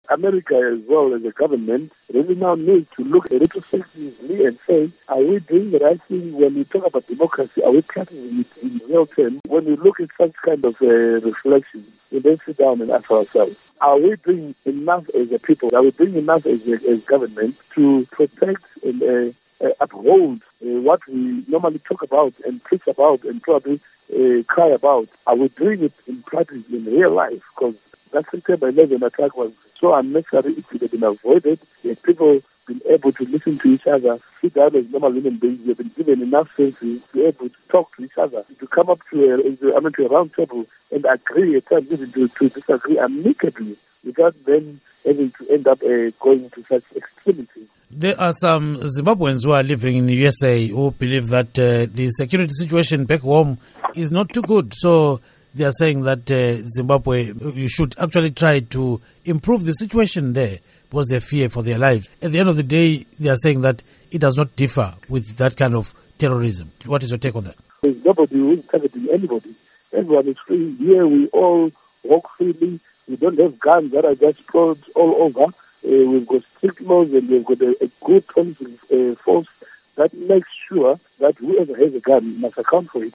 Zimbabwean lawmaker speaks on 9/11 anniversary